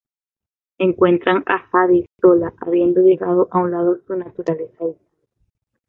so‧la
/ˈsola/